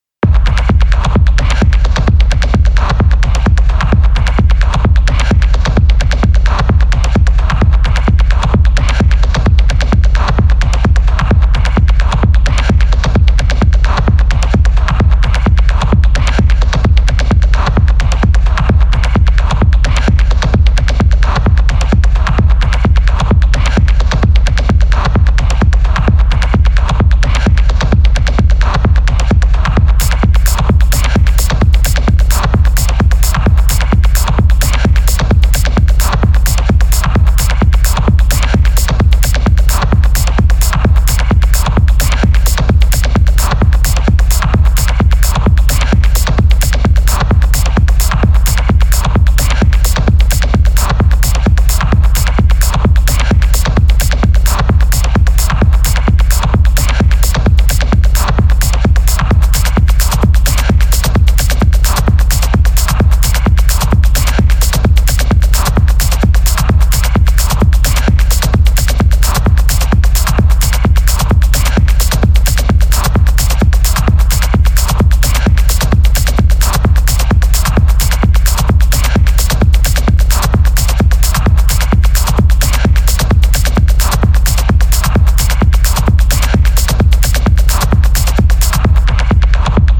techno
raw, direct and purist sound